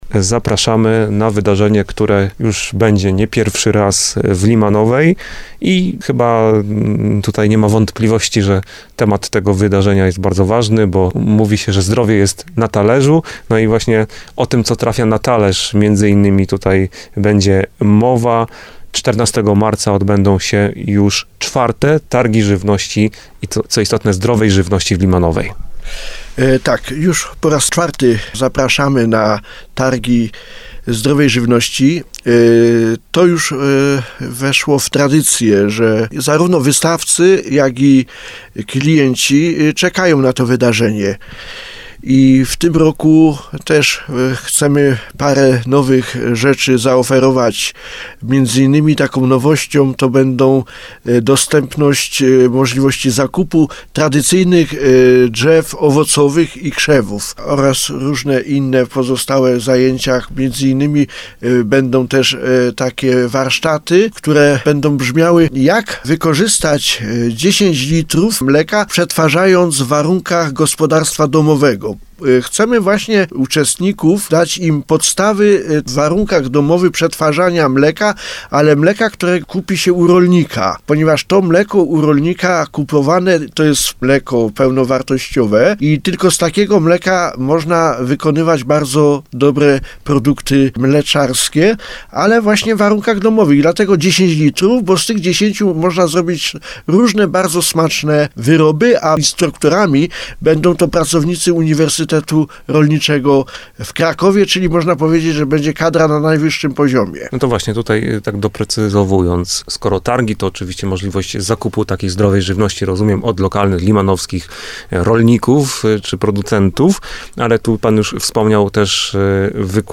Rozmowa z organizatorami